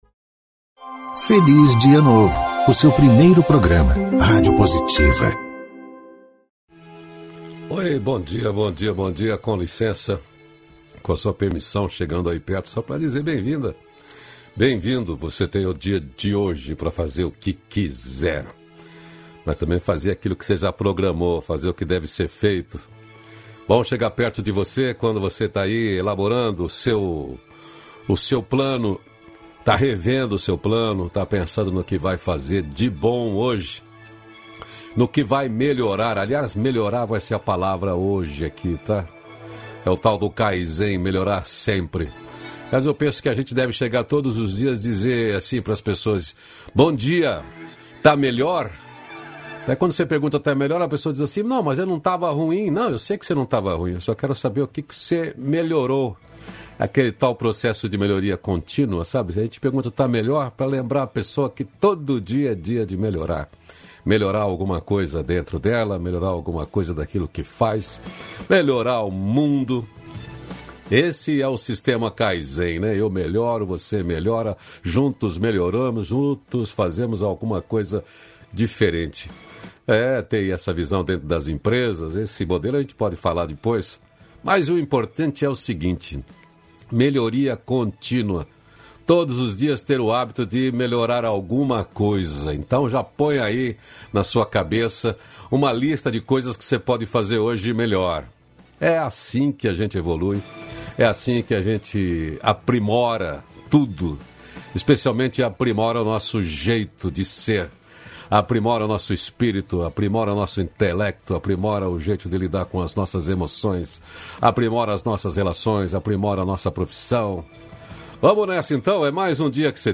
337-feliz-dia-novo-entrevista.mp3